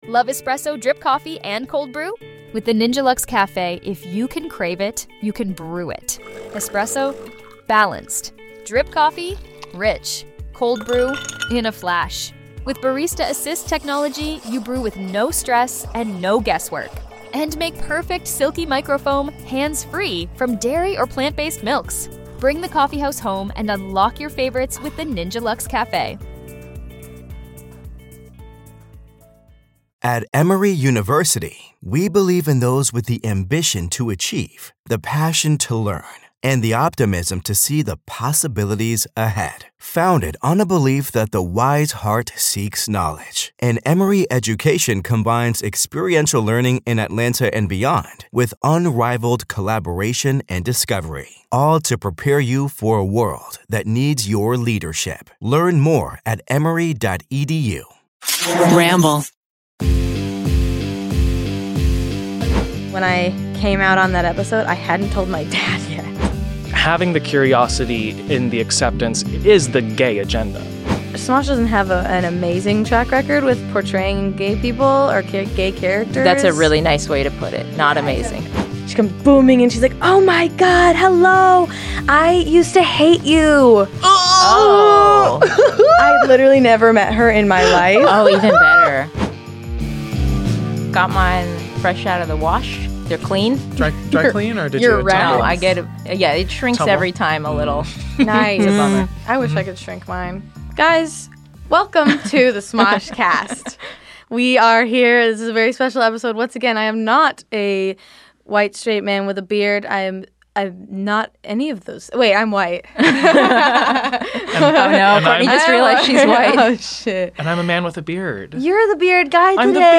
having an open conversation about what coming out was like for them, finding their place in the LGBTQ+ community, and their awkwardly sweet first kisses.